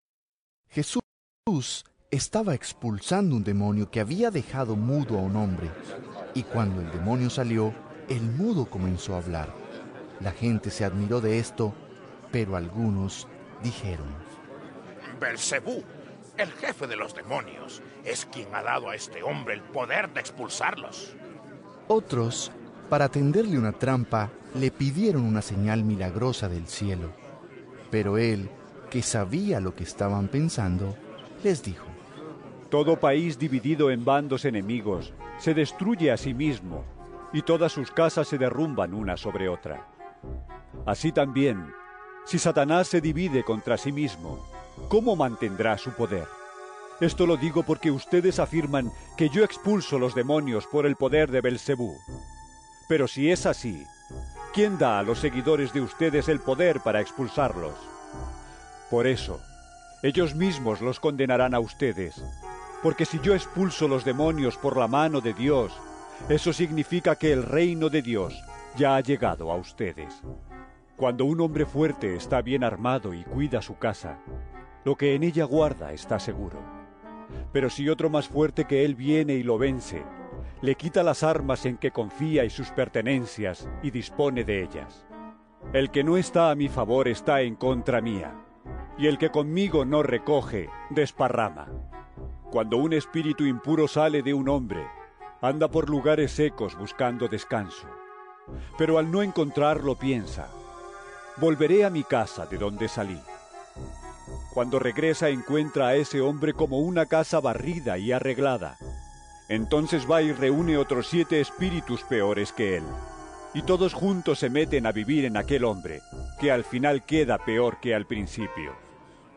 Lc 11 14-26 EVANGELIO EN AUDIO